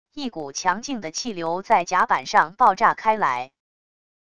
一股强劲的气流在甲板上爆炸开来wav音频